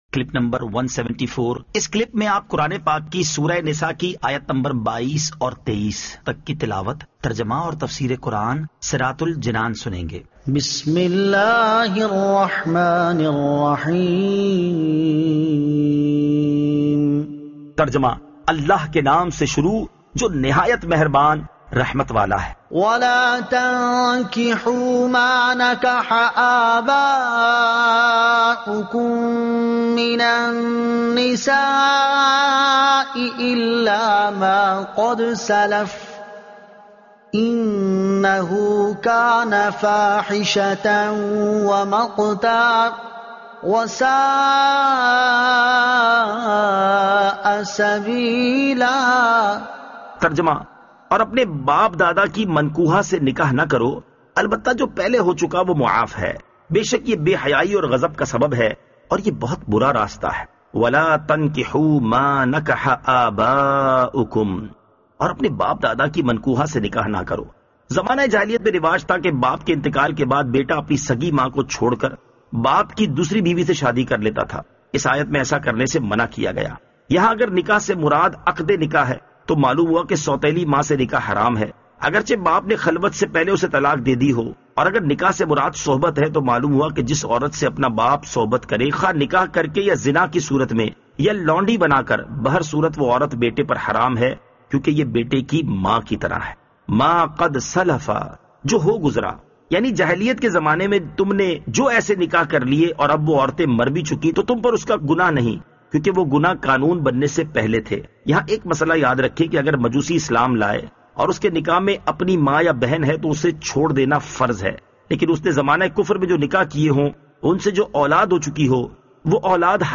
Surah An-Nisa Ayat 22 To 23 Tilawat , Tarjuma , Tafseer